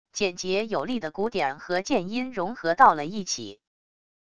简洁有力的鼓点和键音融合到了一起wav音频